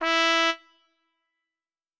Cow_Life_Sim_RPG/Sounds/SFX/Instruments/Trumpets/doot3.wav at a9e1ed9dddb18b7dccd3758fbc9ca9706f824ea5
doot3.wav